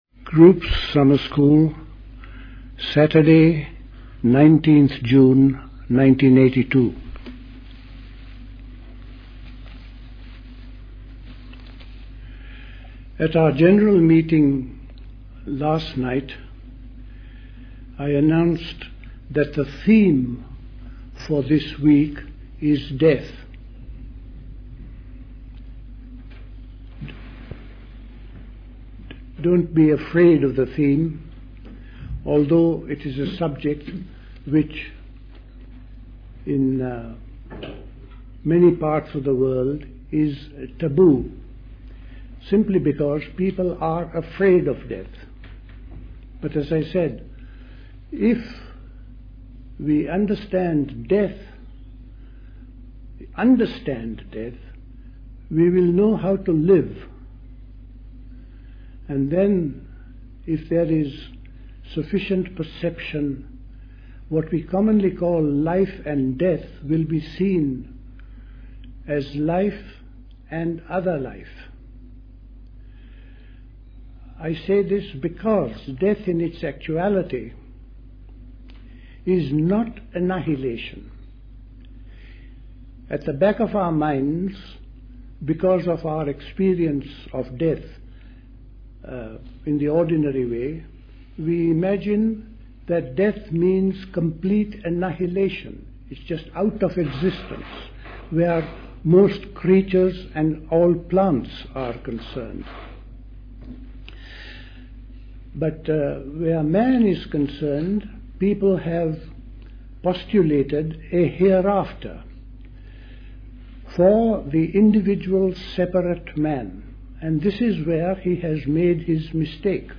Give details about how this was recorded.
The Cenacle Summer School Talks